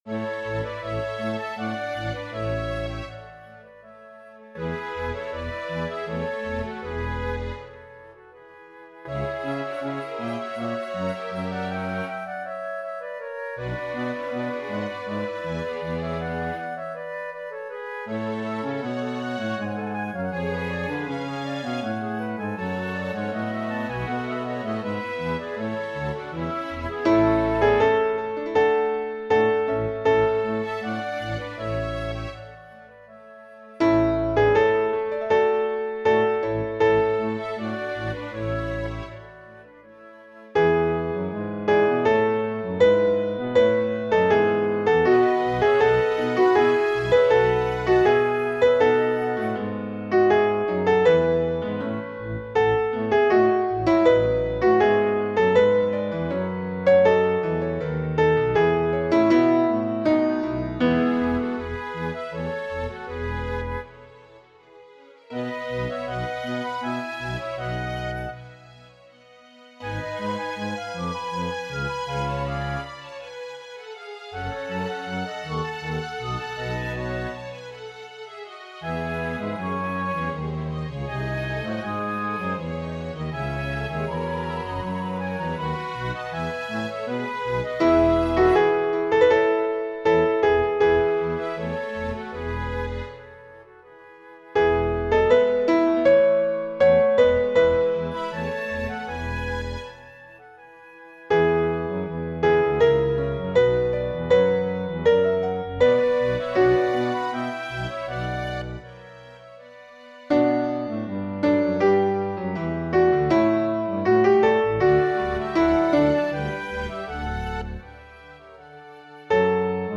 MP3 Dateien von allen Chorstücken nach Register
BWV234 -1a Kyrie_Alt.mp3